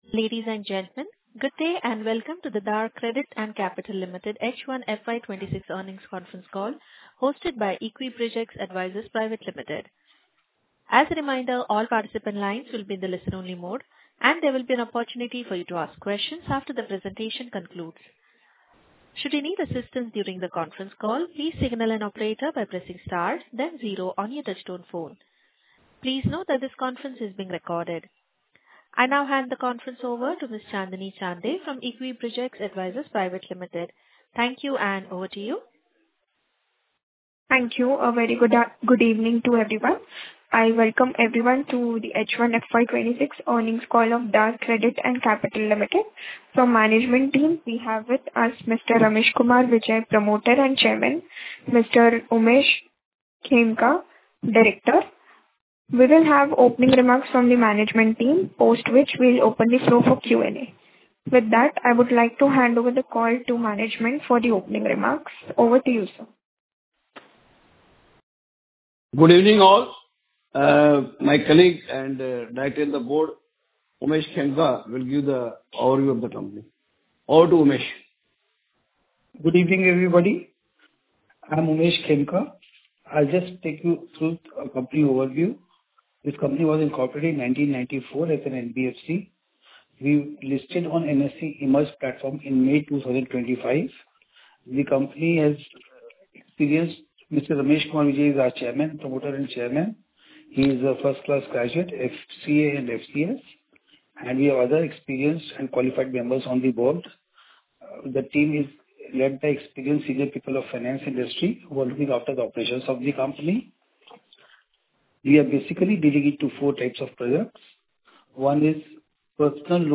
Concalls
Dar Credit - H1 FY26 Earnings call Audio.mp3